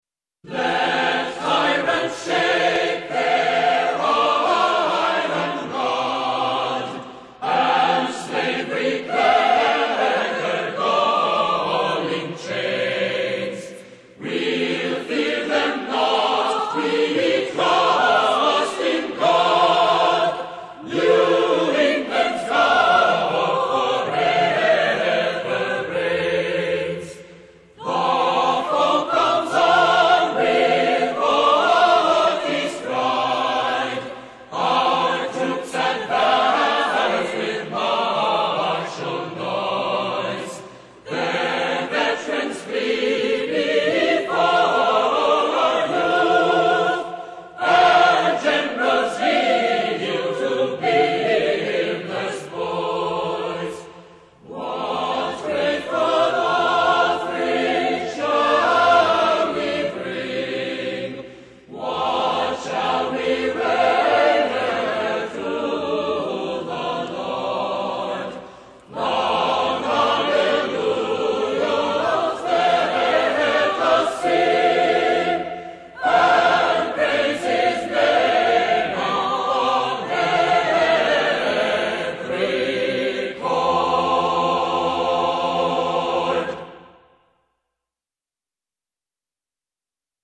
Wait till after the fife and drum rendition.